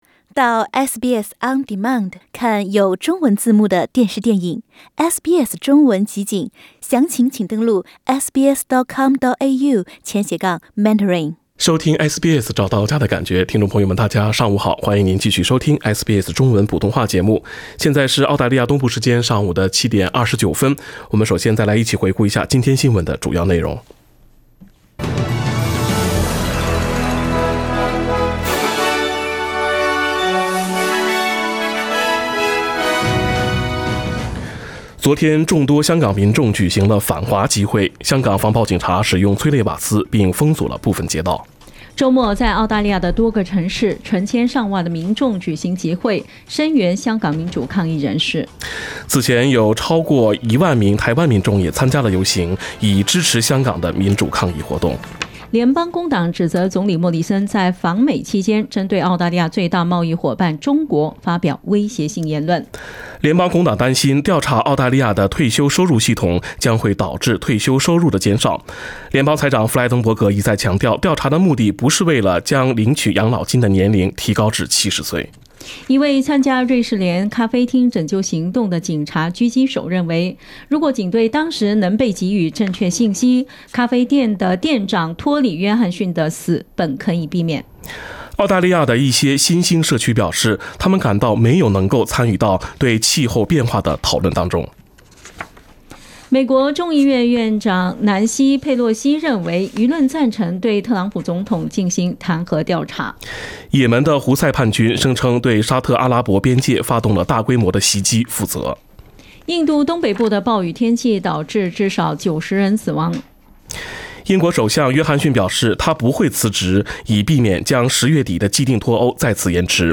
SBS 早新闻 （9月30日）